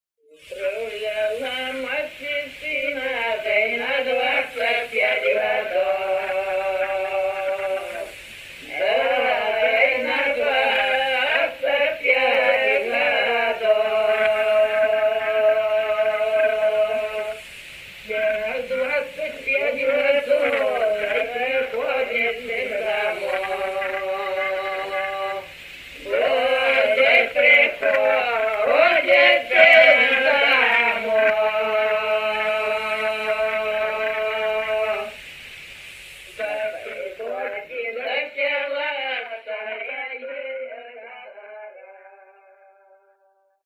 Сьпеўкі ў вёсцы Мялешкавічы